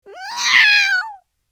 Angry Cat Meow Sound Button - Free Download & Play